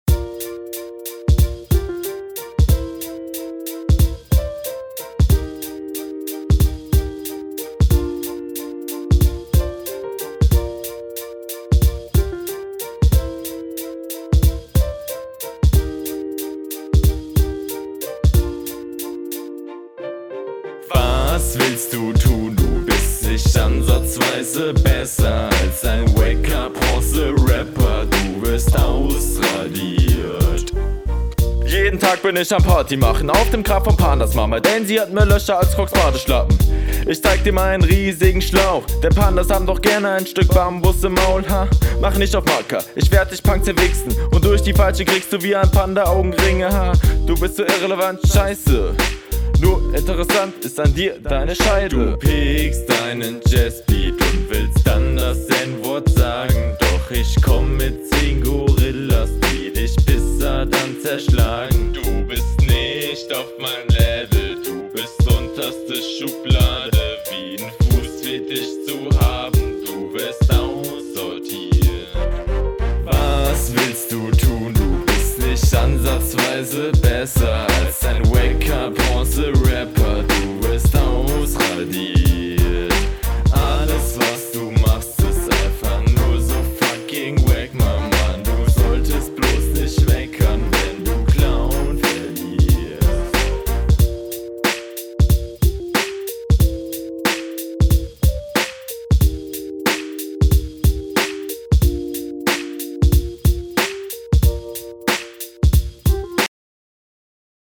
HR2: Flow sehr gechillt.